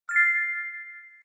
bright_bell_chime2.ogg